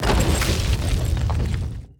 Spells
some SFX
Rock Wall 1.wav